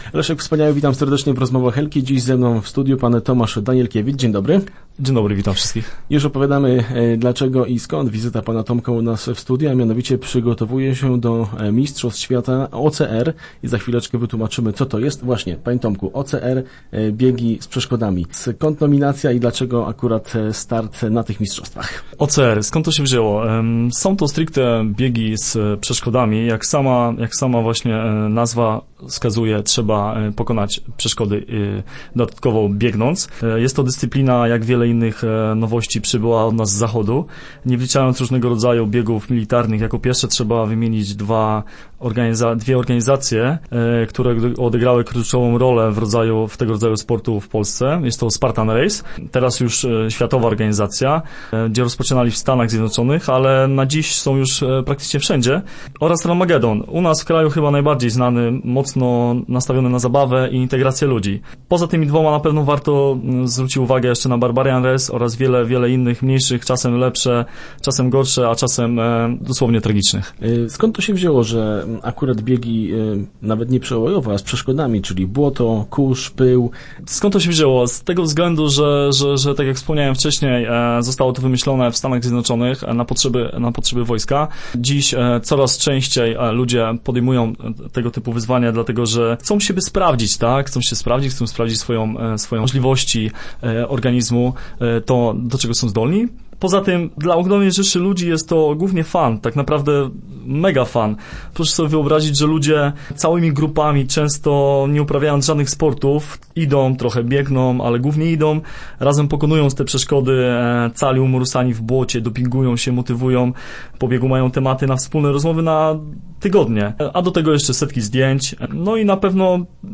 Start arrow Rozmowy Elki arrow Jestem przeszkodowym świrem